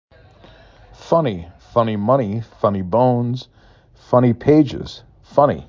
4 Phonemes
f uh n E
ignite_funny_amuz.mp3